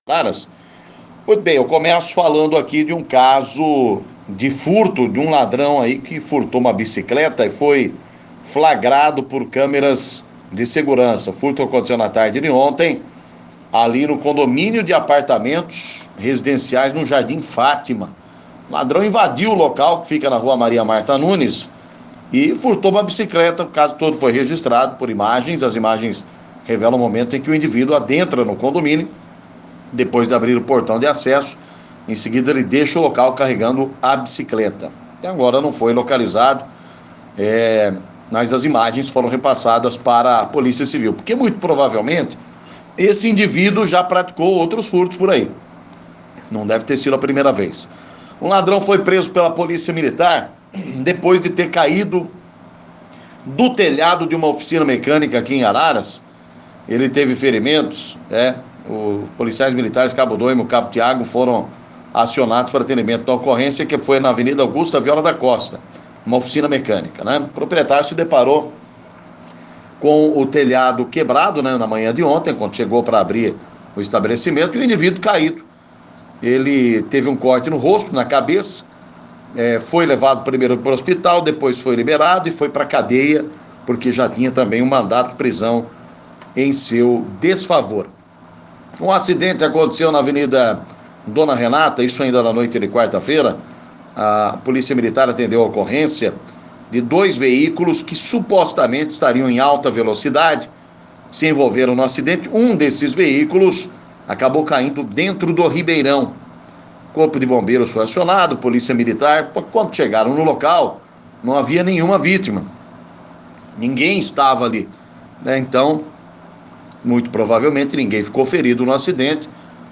Clique no link abaixo e ouça as principais notícias de Araras e região na voz do repórter policial